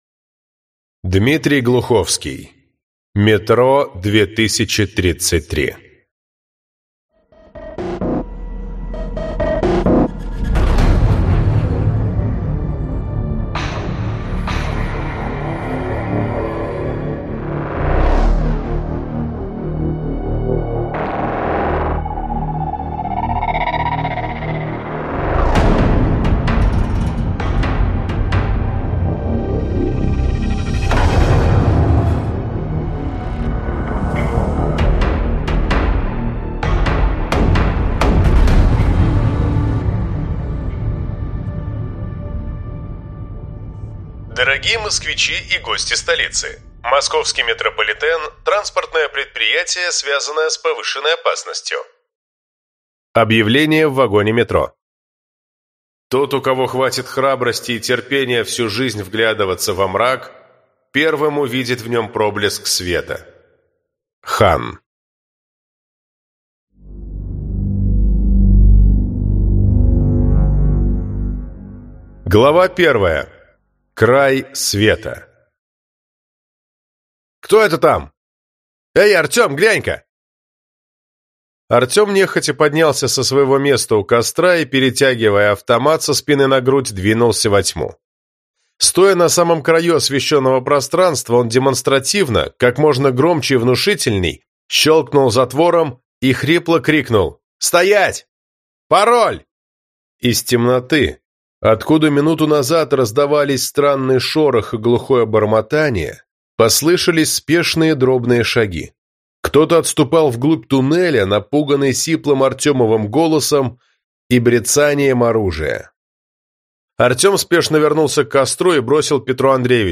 Аудиокнига Метро. Трилогия под одной обложкой - купить, скачать и слушать онлайн | КнигоПоиск